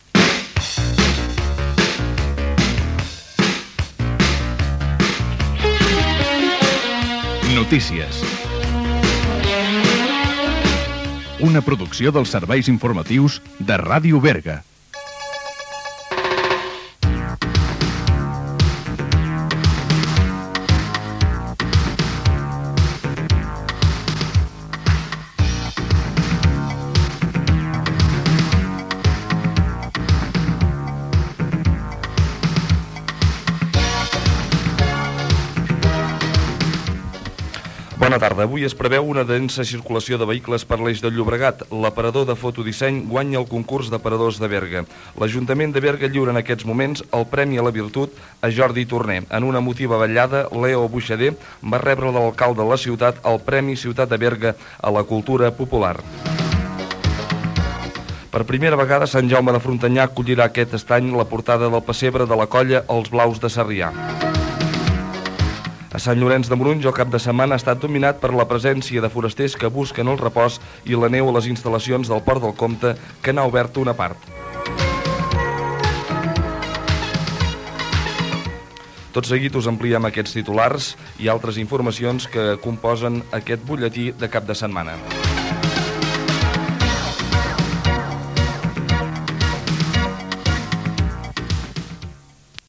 Careta del programa, titulars informatius
Informatiu
FM